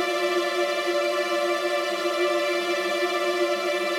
GS_TremString-Dmin9.wav